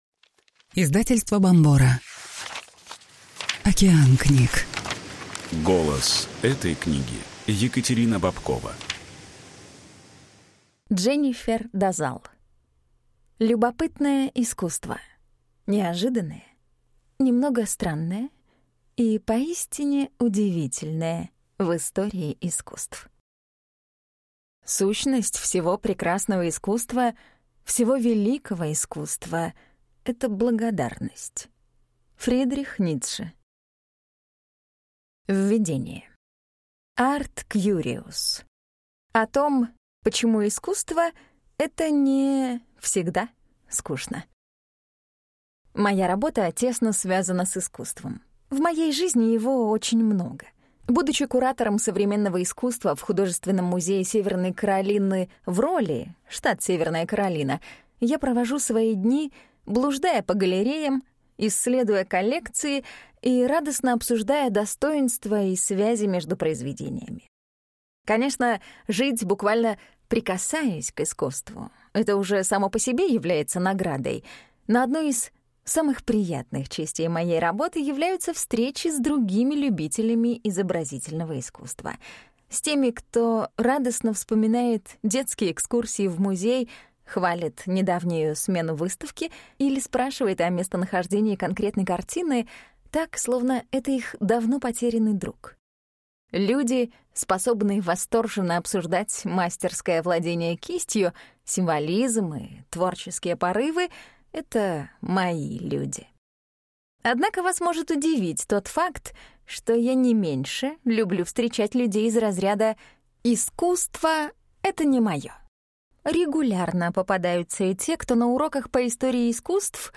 Аудиокнига Любопытное искусство. Самые странные, смешные и увлекательные истории, скрытые за великими художниками и их шедеврами | Библиотека аудиокниг